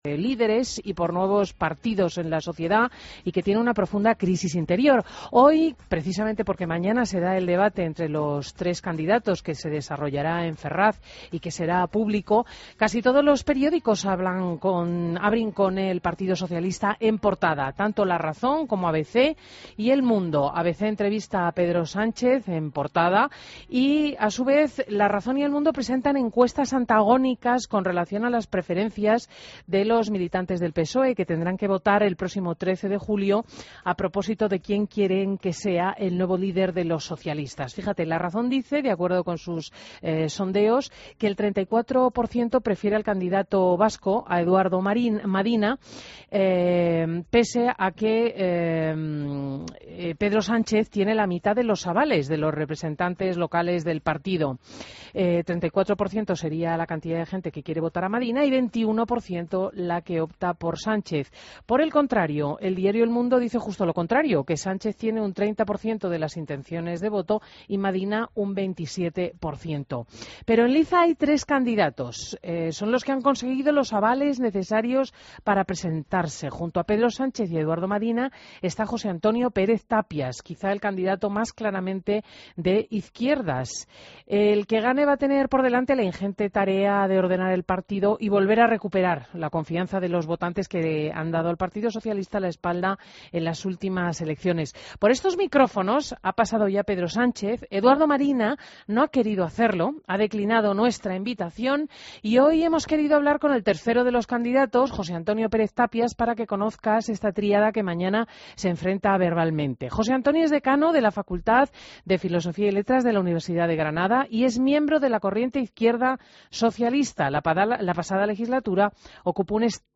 Entrevista a José Antonio Pérez Tapias, candidato a la Secretaría General del PSOE